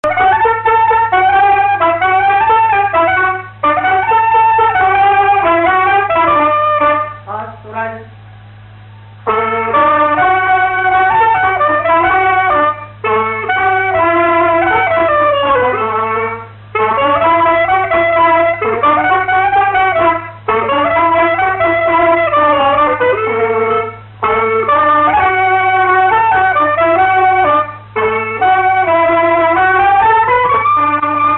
clarinette
Luçon
danse
Pièce musicale inédite